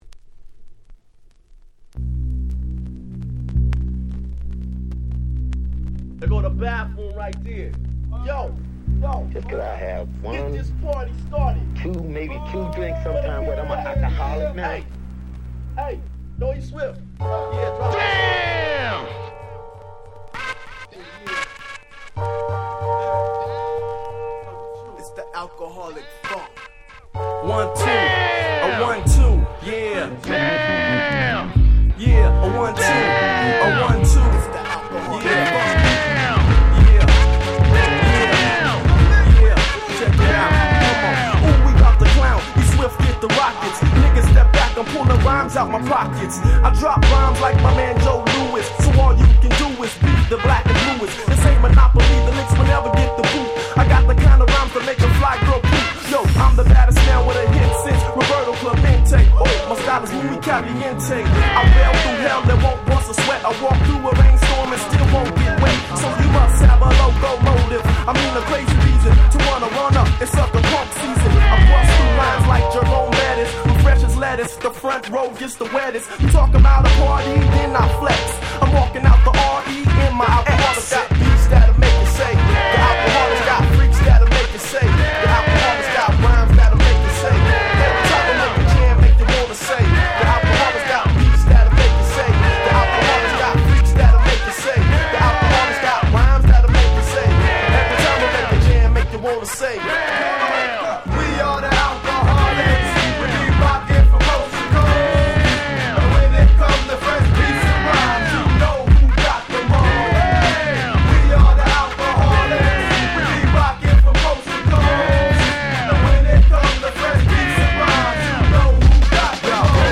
94' Smash Hit Hip Hop !!
90's Boom Bap ブーンバップ